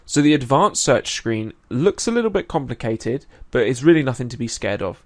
scared-of-strong-unknown-speaker.mp3